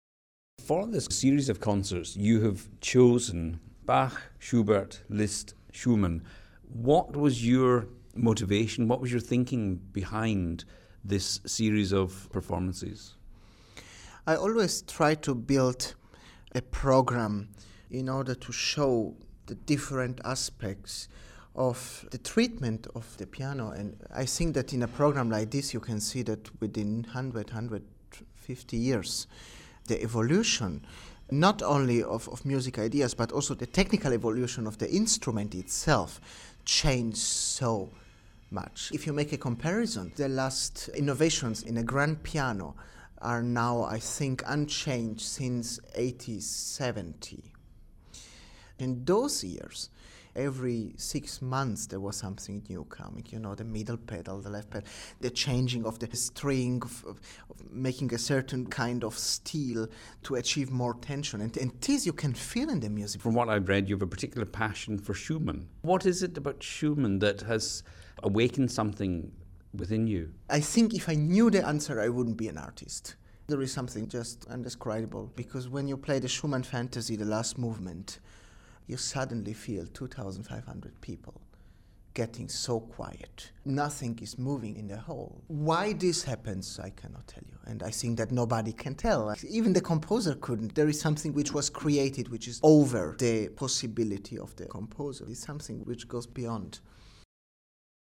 Interview with Francesco Piemontesi